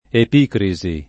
[ ep & kri @ i ]